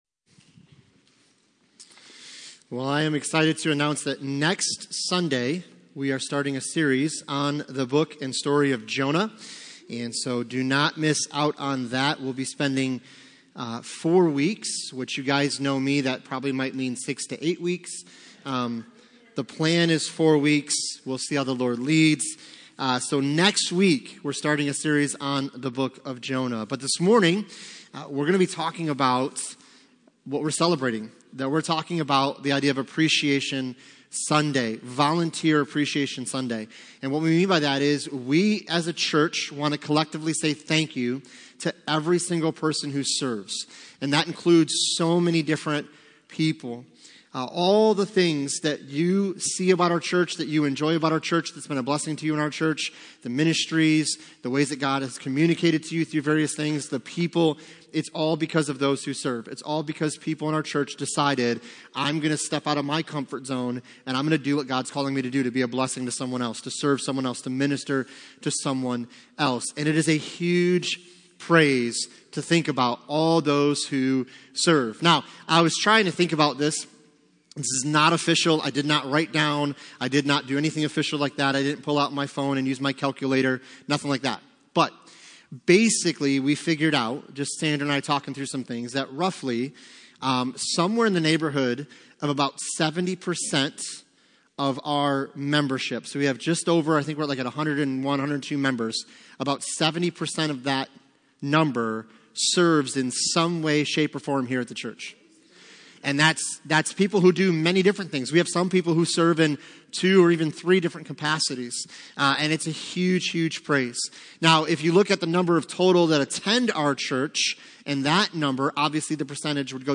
Passage: Exodus 17:12 Service Type: Sunday Morning